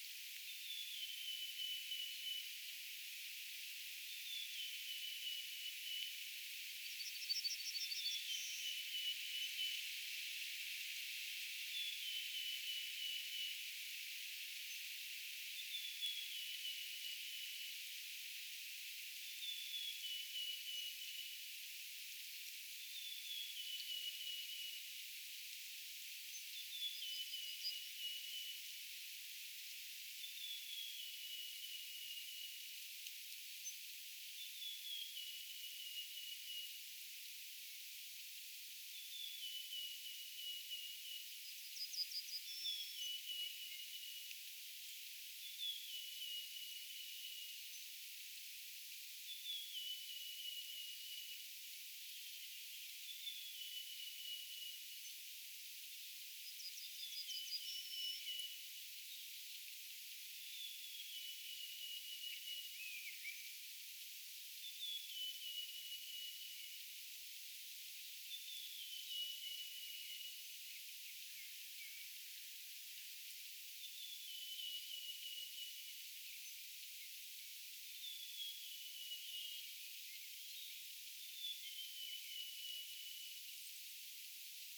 aika hienoa hömötiaisen laulua?
arvaten_poikkeusellisen_hienoa_homotiaisen_laulua.mp3